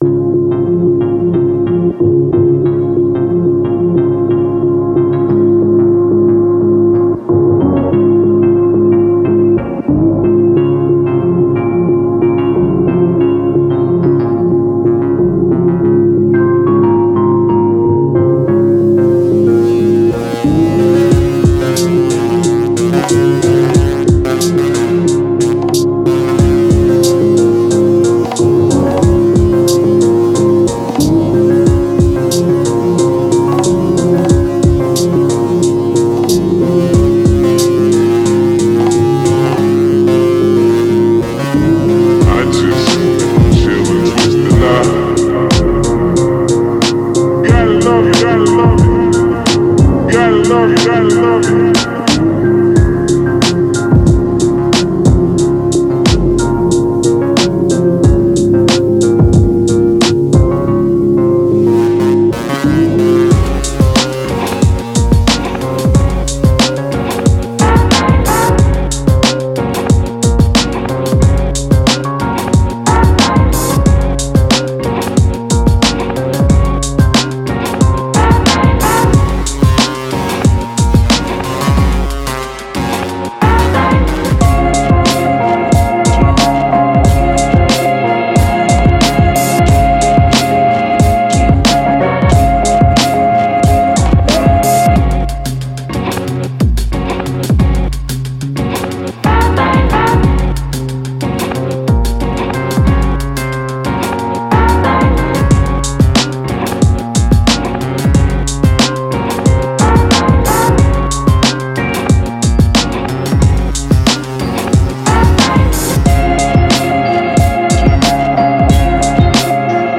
Warning: terrible DJFX looper solo at the end